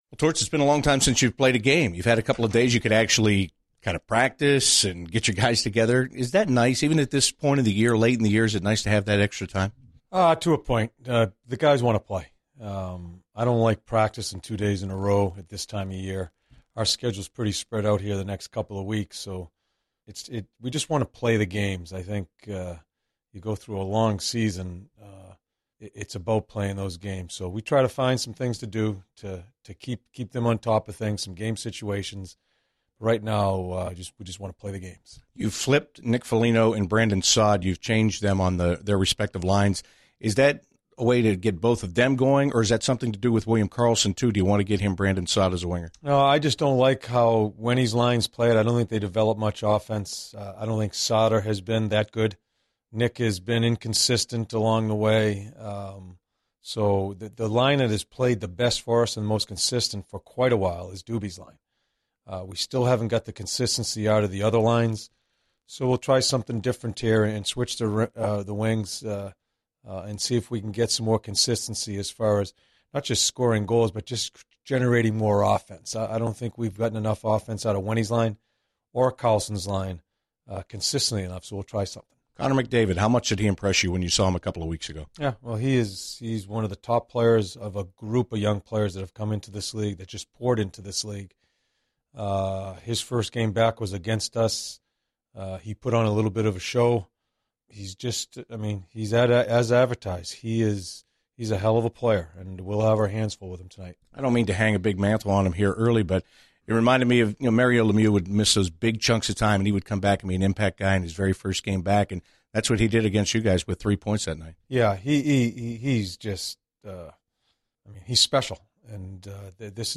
An episode by CBJ Interviews